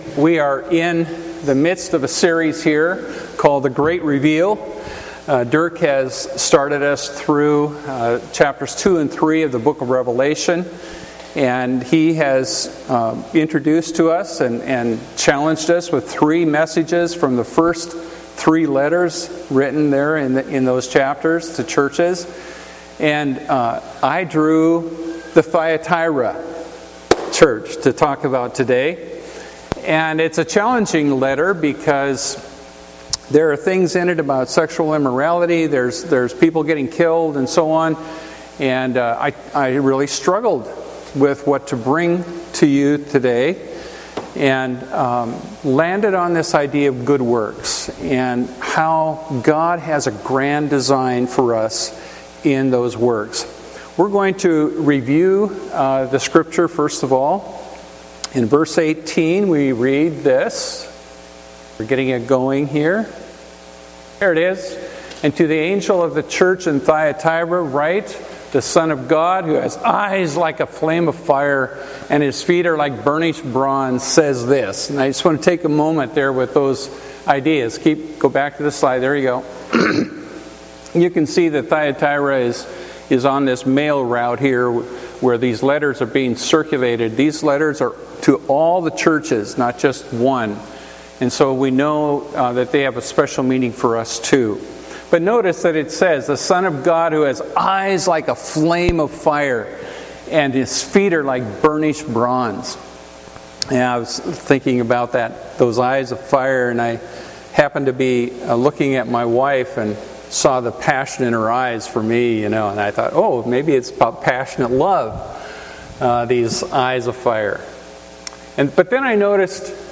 Deeds with Integrity — Emmanuel Reformed Church